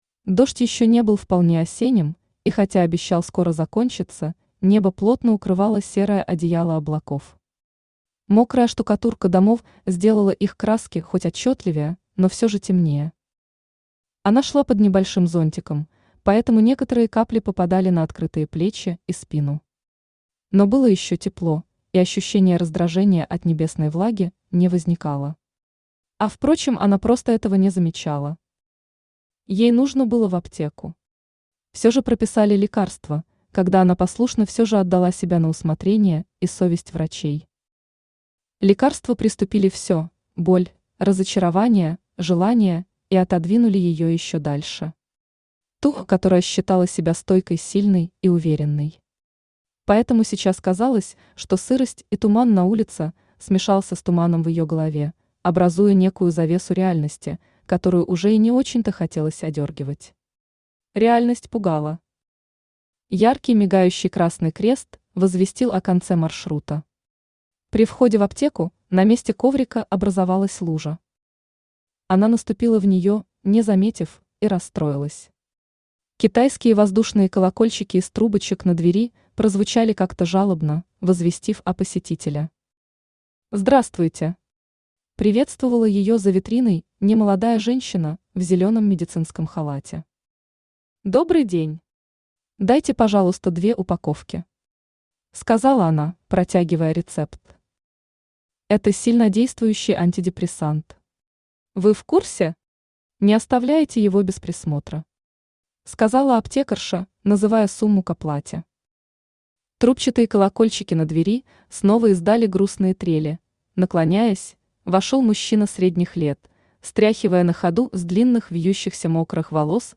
Аудиокнига Ее 72 часа | Библиотека аудиокниг
Aудиокнига Ее 72 часа Автор Илья Дорн Читает аудиокнигу Авточтец ЛитРес.